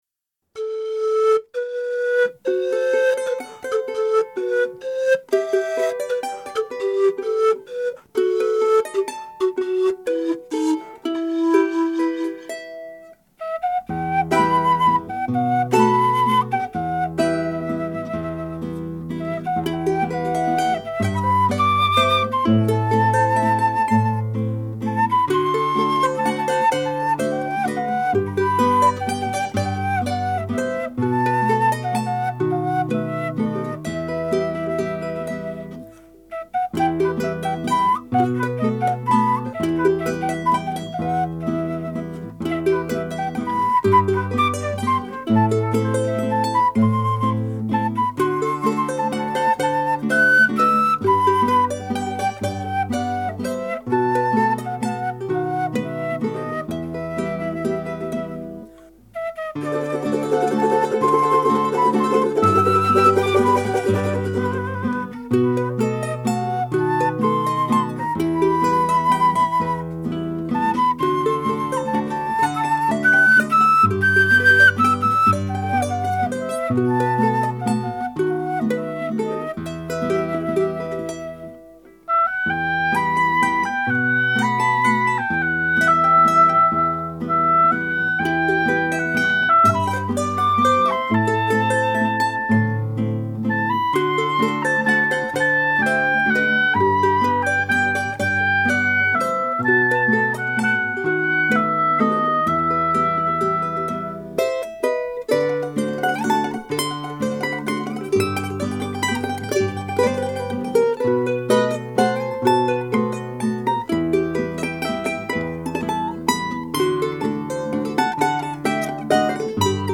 別々に録音した楽器やパソコンで作ったパーカッションをミックスして完成します。
スタジオは鍼灸院の待合室です。
当初、ボーカルでアップしていましたが、 ケーナとチャランゴに変更しました。